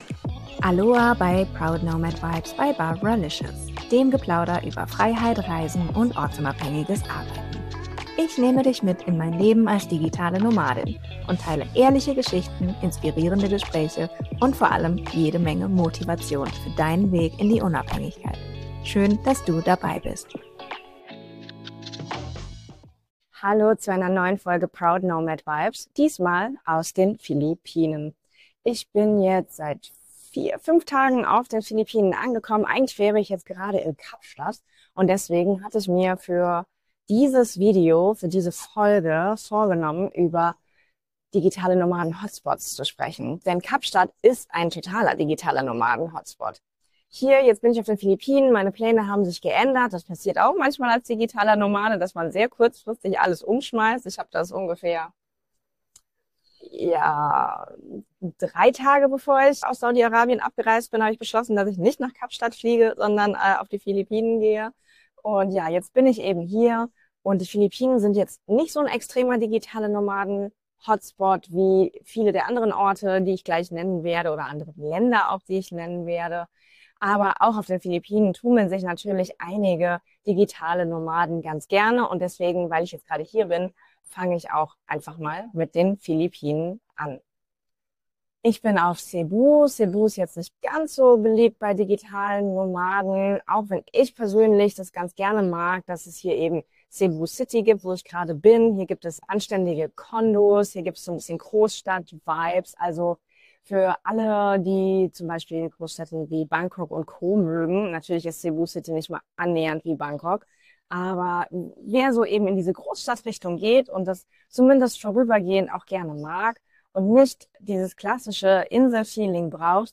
In dieser Solo-Folge von Proud Nomad Vibes